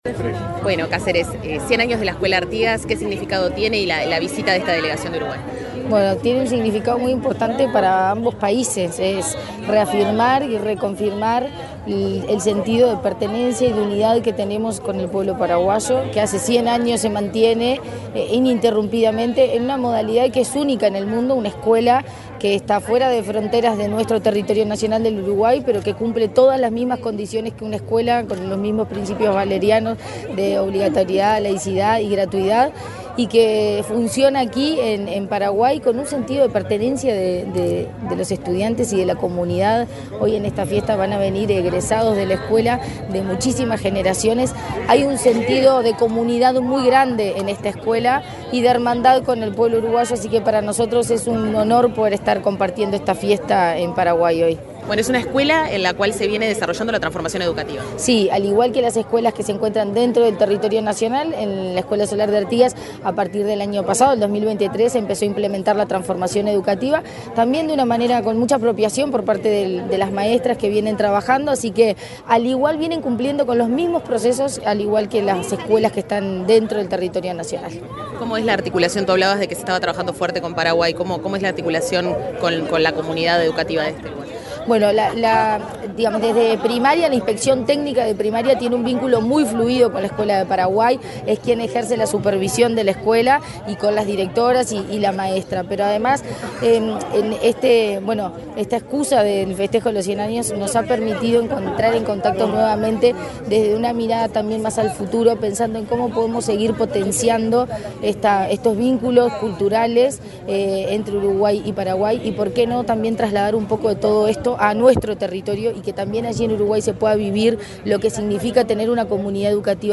Entrevista a la presidenta de la ANEP en Paraguay
Entrevista a la presidenta de la ANEP en Paraguay 28/04/2024 Compartir Facebook X Copiar enlace WhatsApp LinkedIn Antes de la celebración de los 100 años de la escuela uruguaya Artigas, la presidenta de la Administración Nacional de Educación Pública, Virginia Cáceres, dialogó con Comunicación Presidencial.